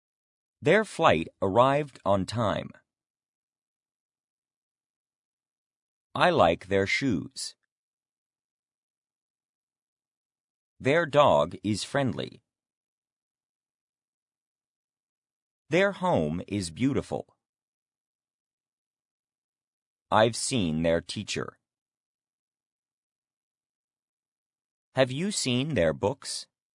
their-pause.mp3